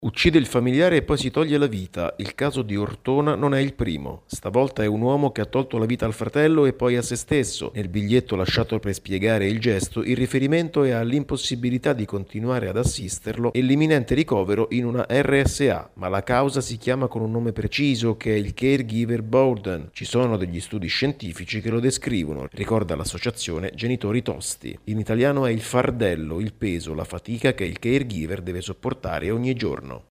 Dopo il caso di Ortona le associazioni chiedono il riconoscimento del lavoro di cura per i caregiver. Il servizio